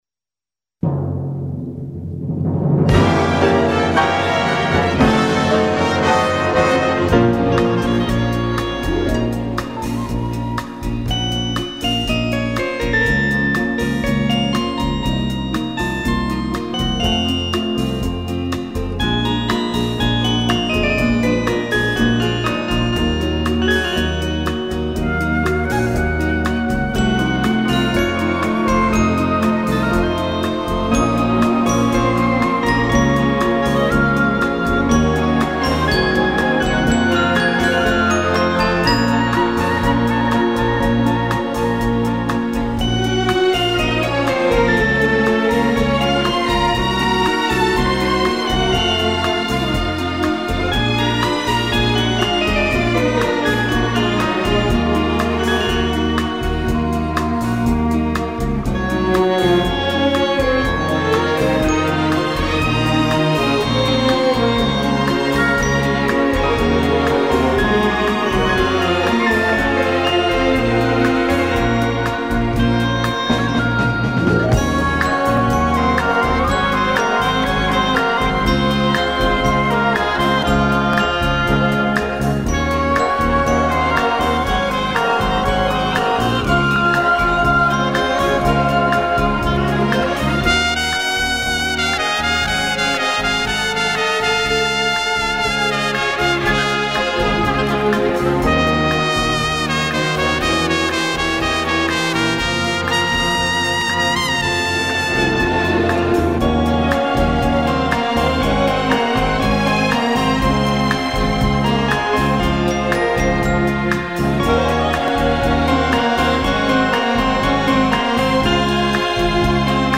Запись с диска из своей коллекции.